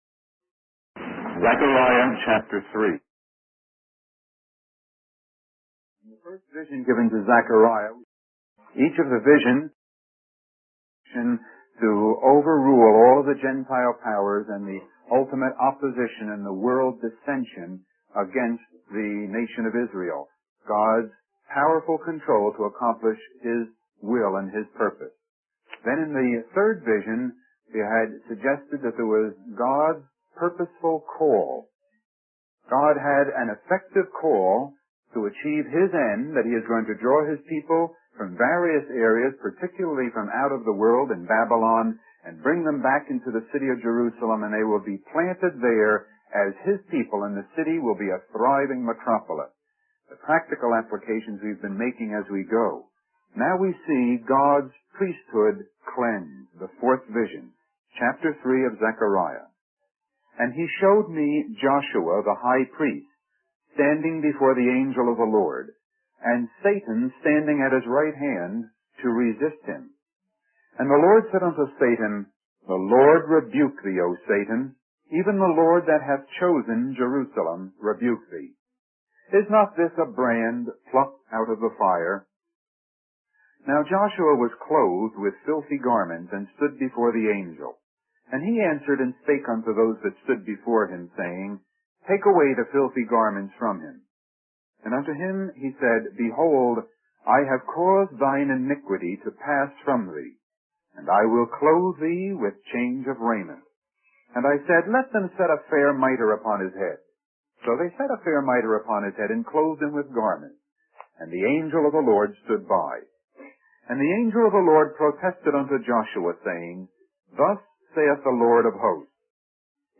In this sermon, the speaker discusses a vision from the book of Zechariah. The vision is divided into three sections: the problem faced by Zechariah and the Lord, the purging that God undertakes and accomplishes, and the prediction of the prophecy.